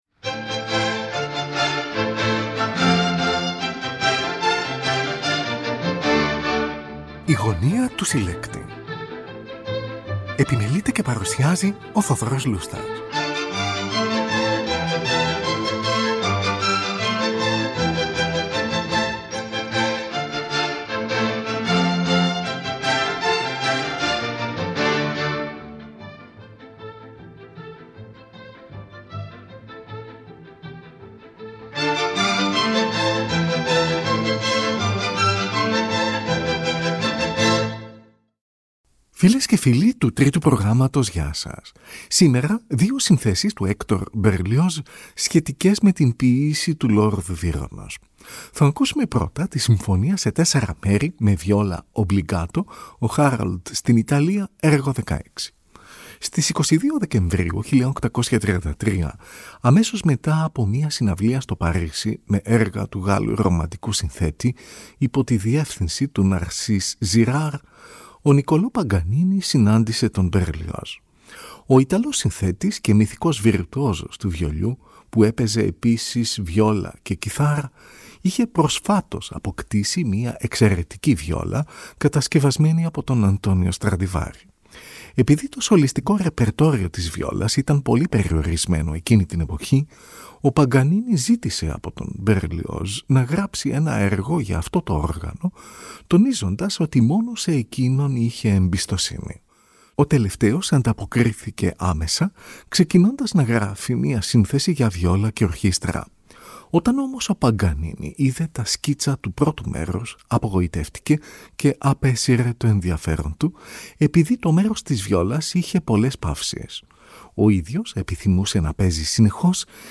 Ακούγονται οι εξής συνθέσεις του Hector Berlioz : Συμφωνία σε τέσσερα μέρη, με βιόλα obbligato, «Ο Harold στην Ιταλία«, έργο 16.